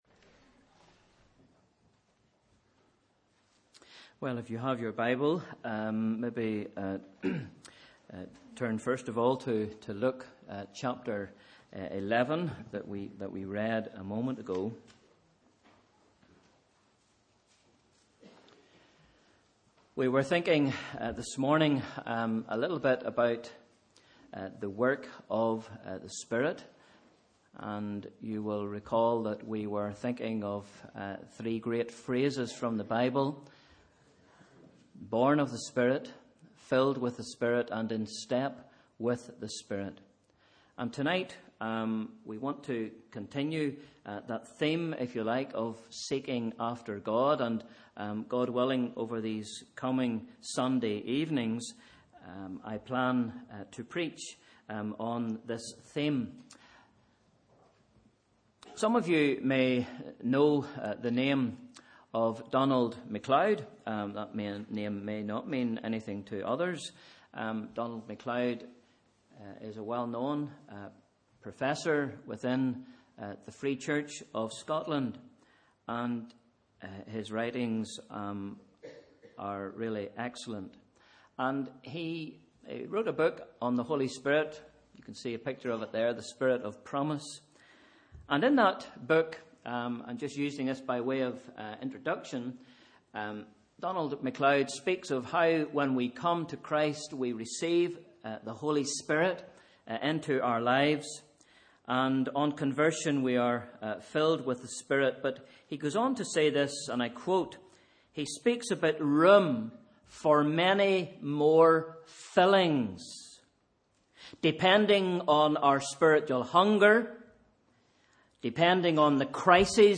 Sunday 10th January 2016 – Evening Service